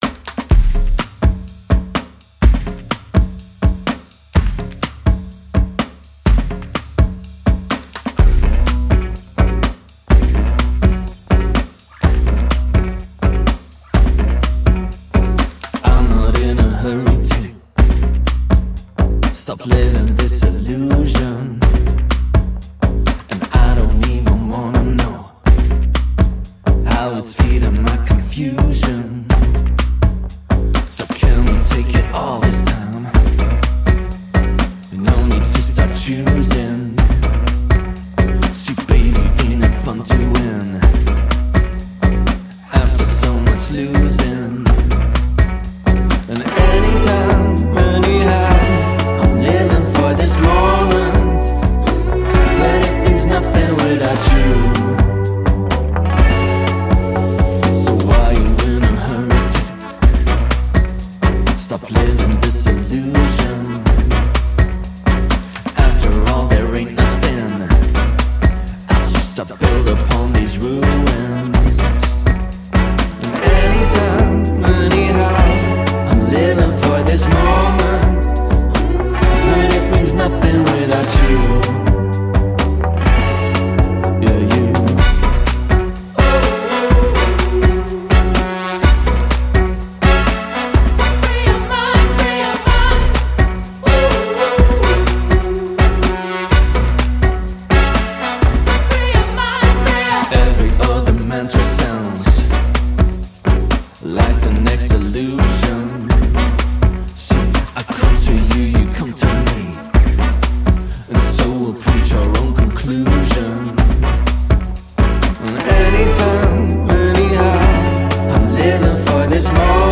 Art Rock